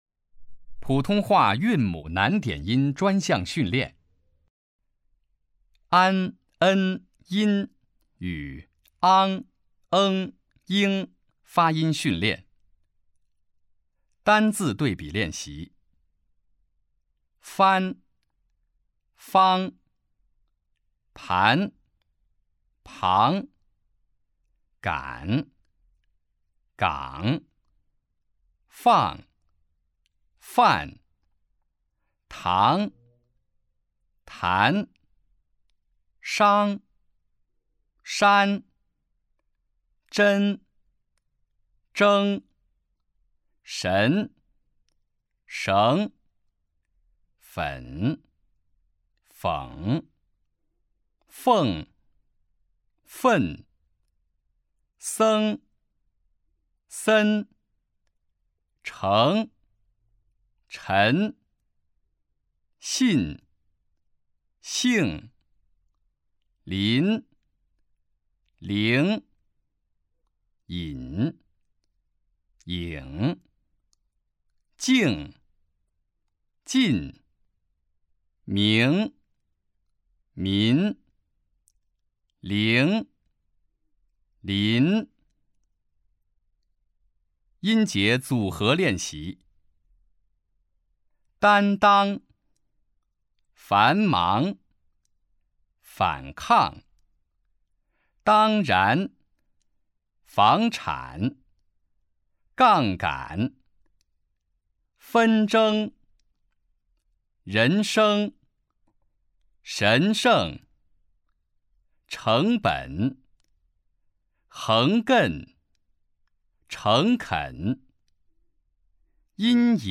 普通话水平测试 > 普通话水平测试资料包 > 02-普通话水平测试提升指导及训练音频
004普通话韵母难点音专项训练.mp3